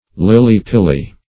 Search Result for " lilly-pilly" : The Collaborative International Dictionary of English v.0.48: Lilly-pilly \Lil"ly-pil`ly\ (l[i^]l"l[y^]-p[i^]l`l[y^]), n. (Bot.) An Australian myrtaceous tree ( Eugenia Smithii ), having smooth ovate leaves, and panicles of small white flowers.